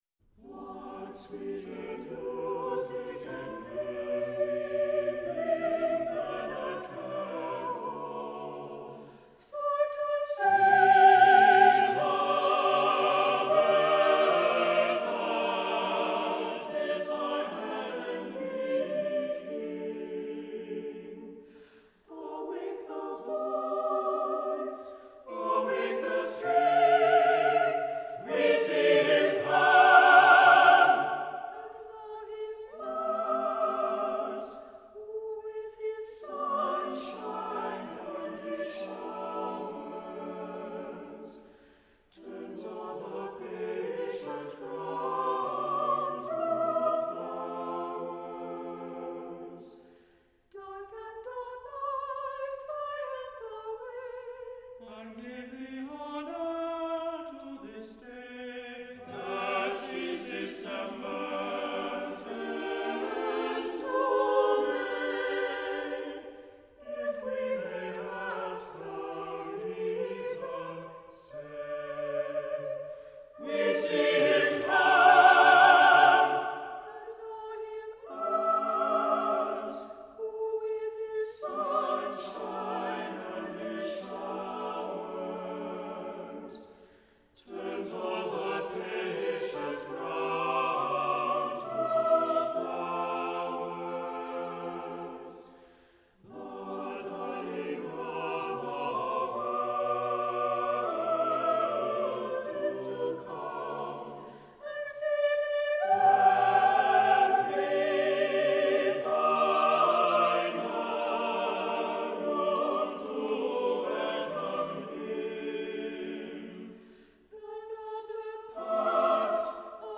Accompaniment:      None;Reduction
Music Category:      Choral